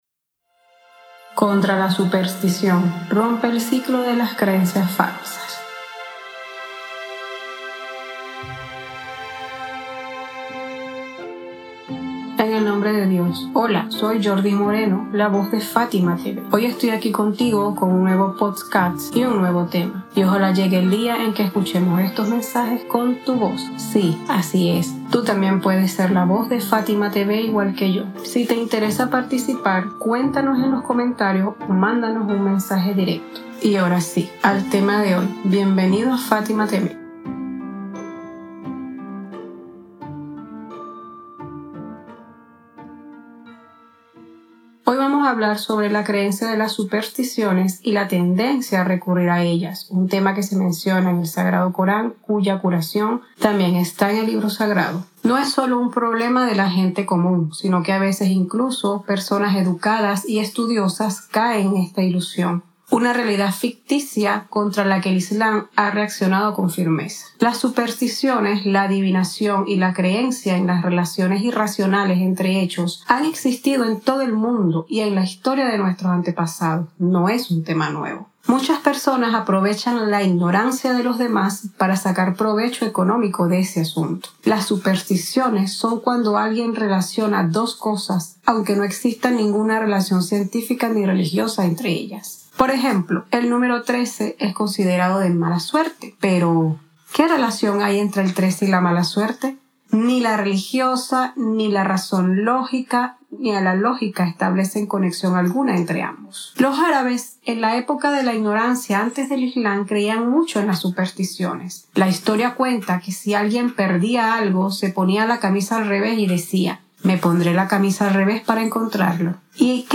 🎙 Locutora